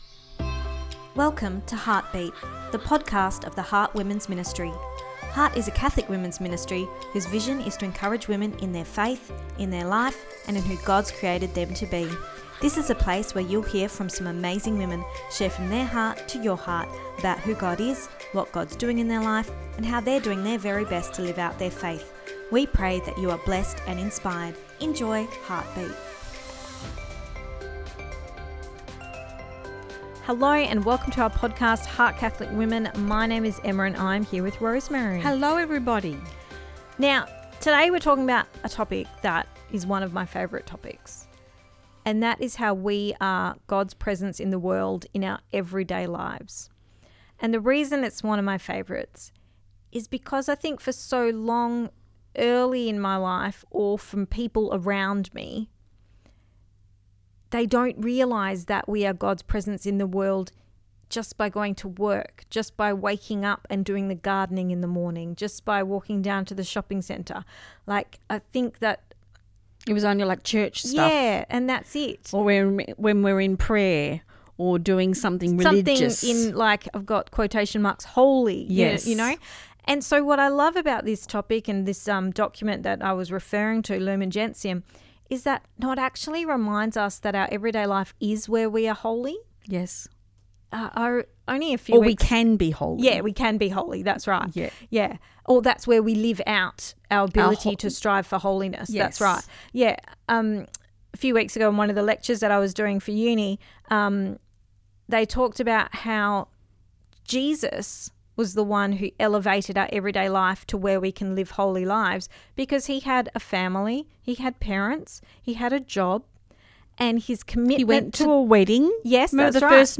Ep246 Pt2 (Our Chat) – You Are His Presence in the World